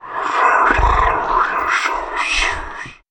Звук с шепотом демона
zvuk-s-shepotom-demona.mp3